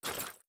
Grenade Sound FX
Throw2.wav